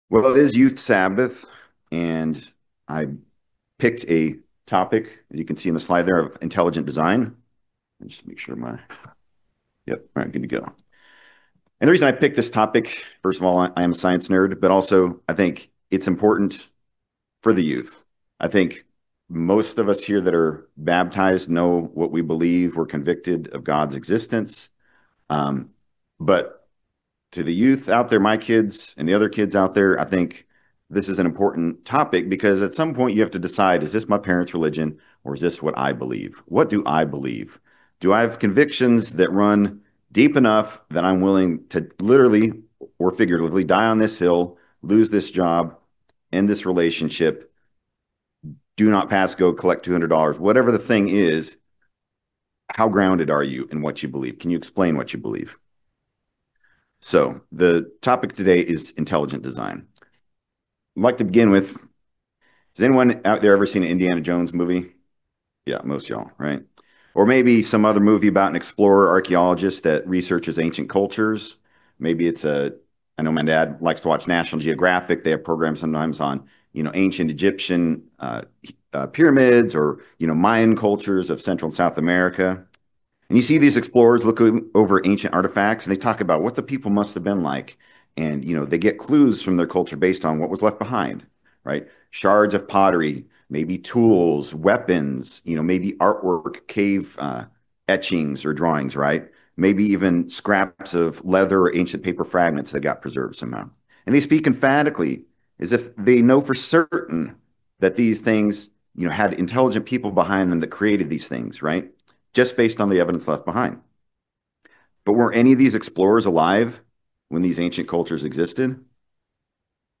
This sermon expertly explains creation vs evolution.